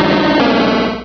Cri de Cerfrousse dans Pokémon Rubis et Saphir.